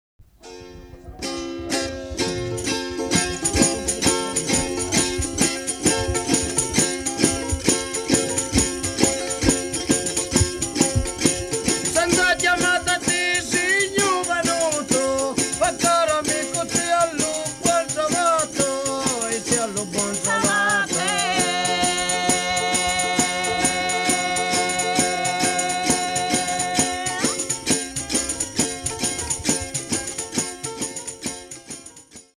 In this recording, as often, the singers discuss their lines between verses, and comment upon the execution of the proceeding verse.
vocals
guitar
tambourine